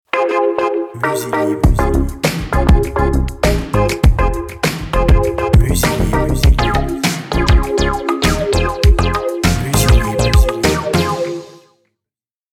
Jingle à tendance retro funk avec des plans synthwave!
BPM Moyen